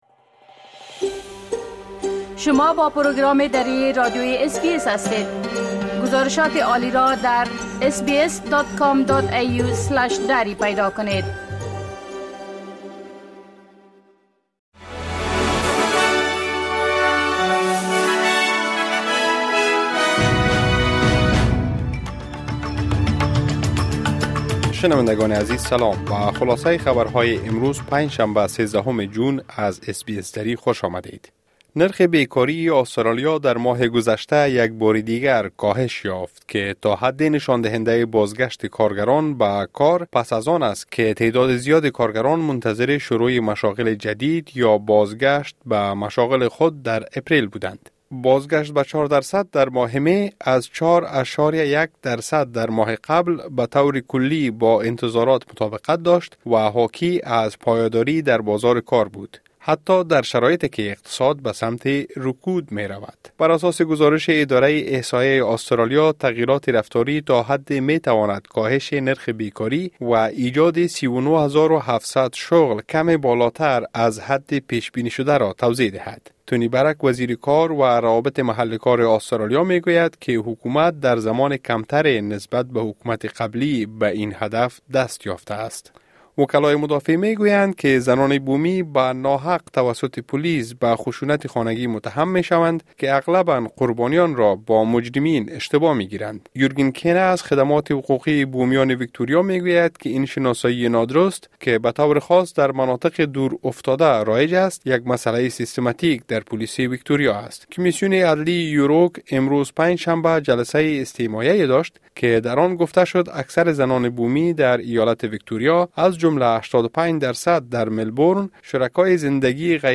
خلاصۀ مهمترين خبرهای روز از بخش درى راديوى اس بى اس|۱۳ جون ۲۰۲۴